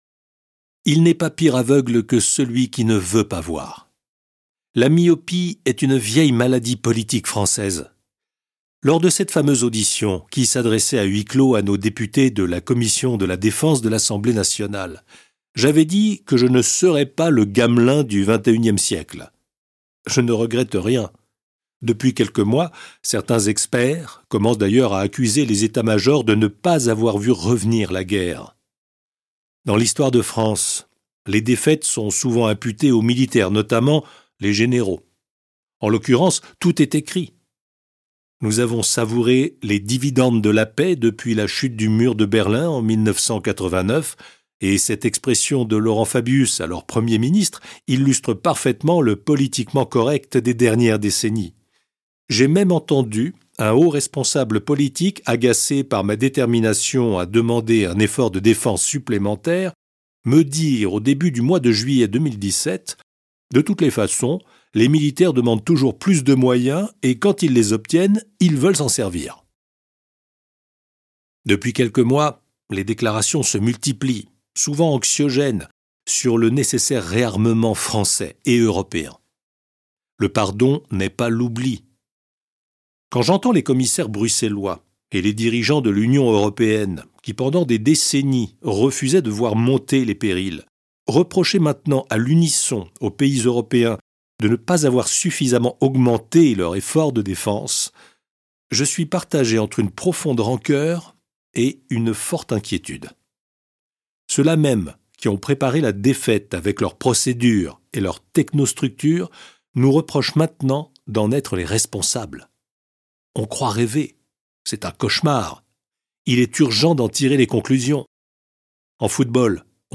Livre audio Pour le succès des armes de la France de Pierre de Villiers